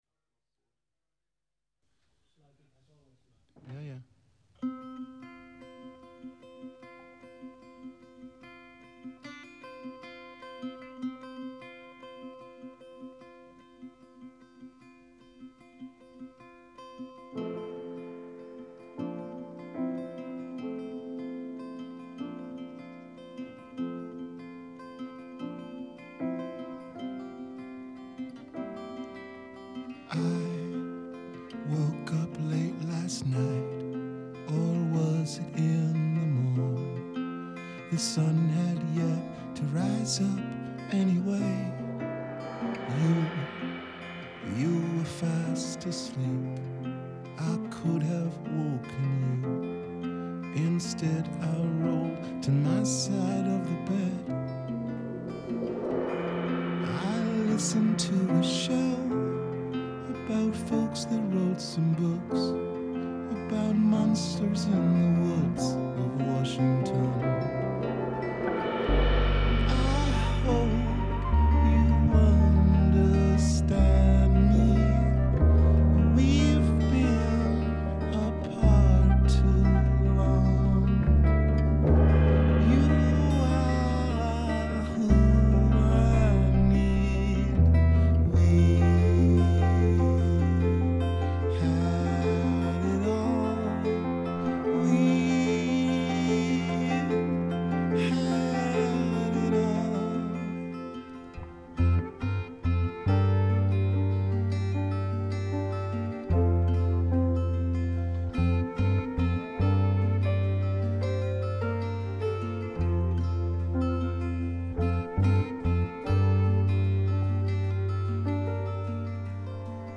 Monsters monsters_ableton.mp3 Monsters The Available Version: e1 Author: the available Category: Rehearsal recordings Date: October 6, 2012 9.4 MiB 20 Downloads Details…